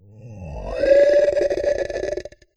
SFX
Monster_Death1.wav